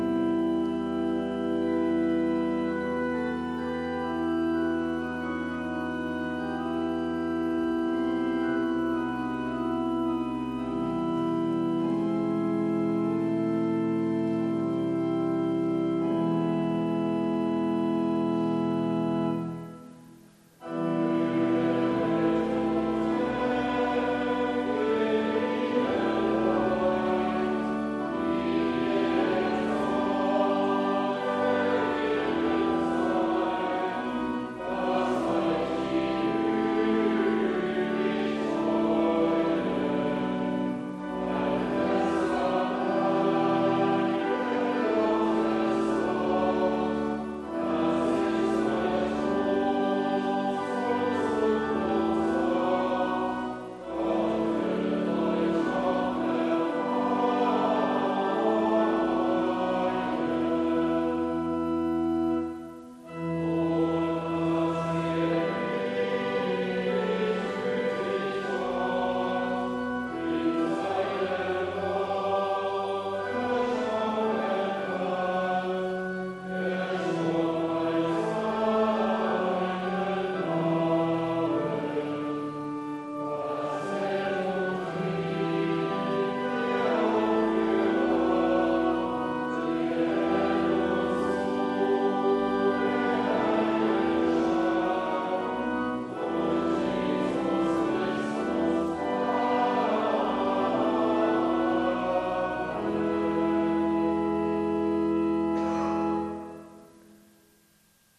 "Kommt her zu mir", spricht Gottes Sohn... (LG 327,8+9) Evangelisch-Lutherische St. Johannesgemeinde
Audiomitschnitt unseres Gottesdienstes vom 2. Sonntag nach Trinitatis 2022.